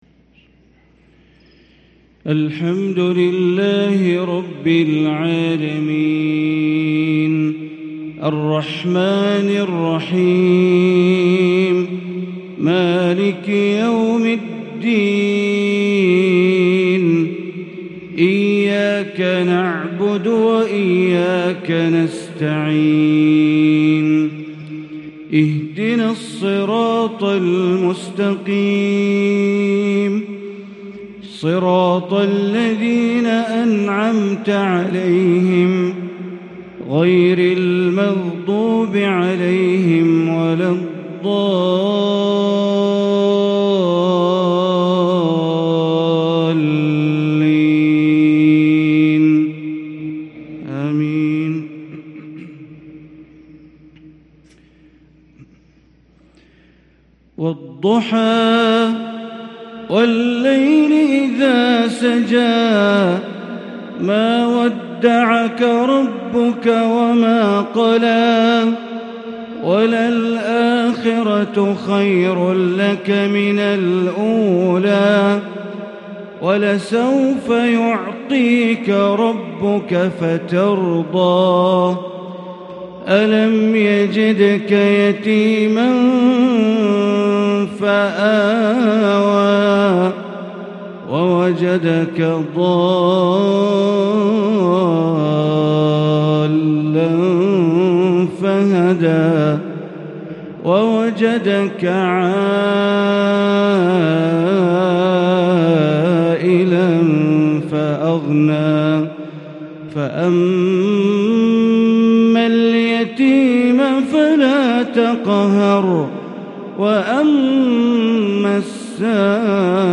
مغرب الجمعة 7 محرم 1444هـ سورتي الضحى و الشرح | Maghrib prayer from Surah Ald-Duha & Al-Sharih 5-8-2022 > 1444 🕋 > الفروض - تلاوات الحرمين